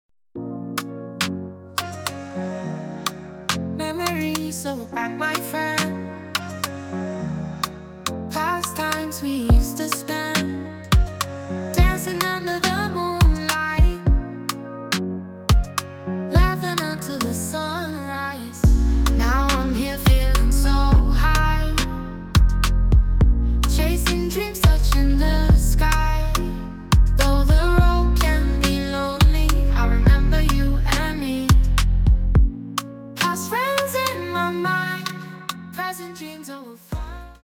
An incredible Afrobeats song, creative and inspiring.